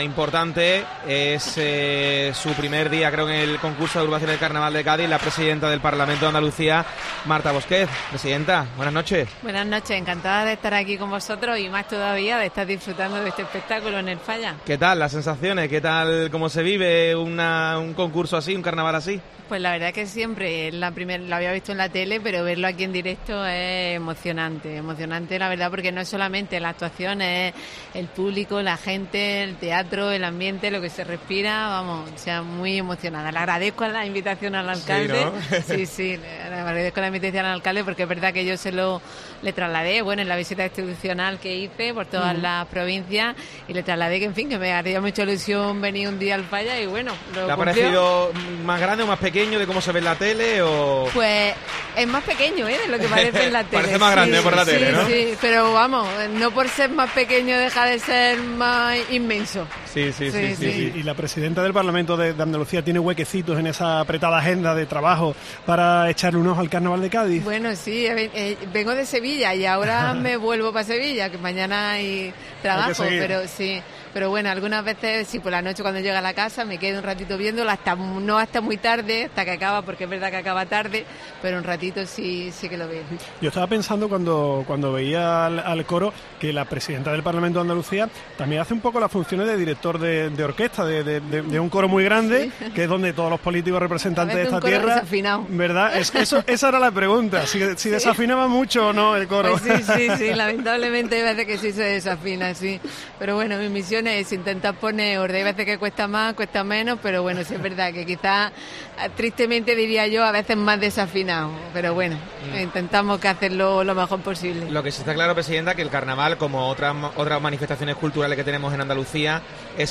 AUDIO: Entrevista a la presidenta del Parlamento de Andalucía Marta Bosquet en los micrófonos de COPE